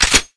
lasersg_clipin2.wav